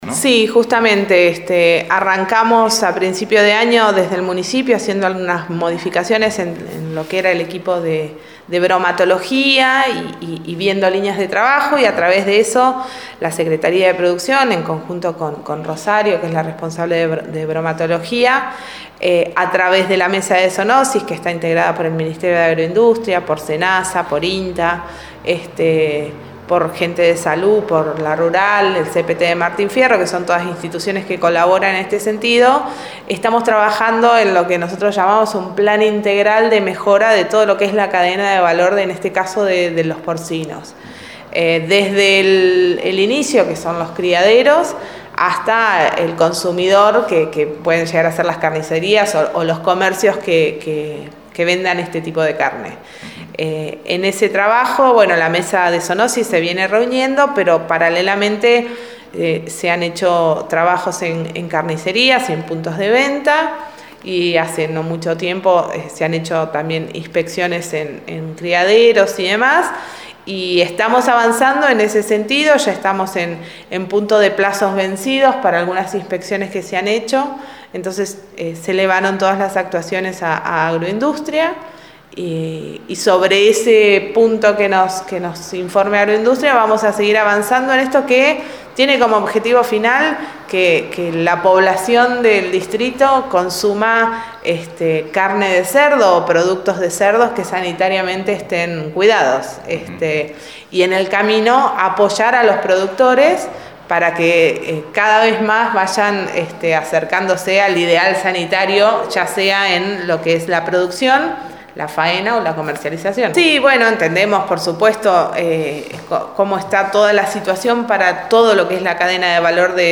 Varias áreas municipales están trabajando en el control y ayuda a los productores de carne porcina del distrito. Clarisa Fabris Secretaria de Producción del Municipio nos comenta de esta actividad.